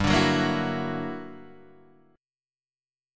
G Minor Major 13th
GmM13 chord {3 5 4 3 5 2} chord